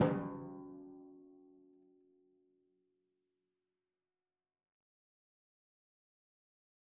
Timpani5_Hit_v4_rr1_Sum.wav